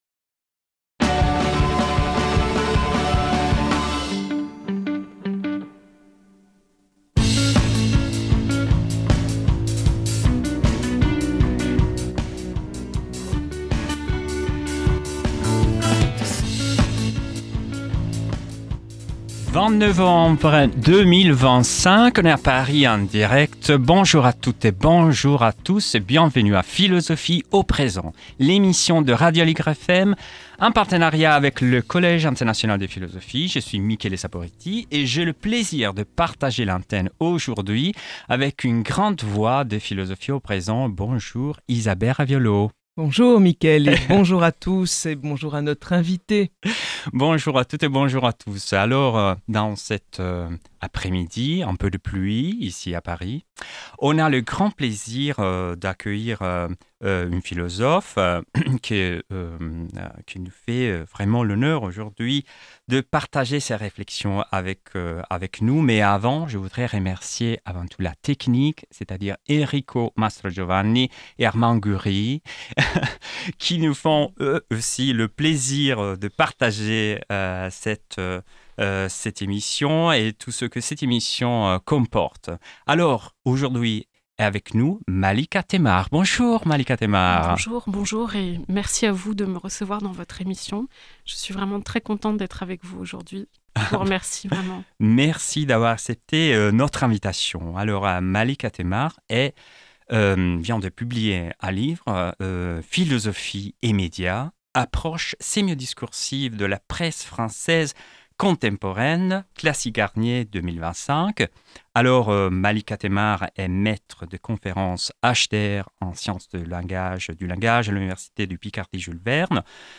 Musique: G. Allegri, Miserere